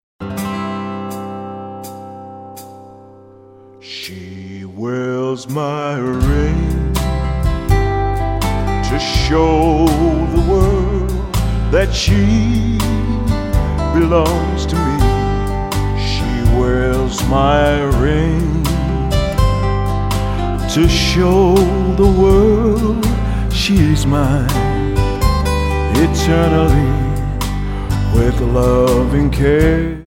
Tonart:C Multifile (kein Sofortdownload.
Die besten Playbacks Instrumentals und Karaoke Versionen .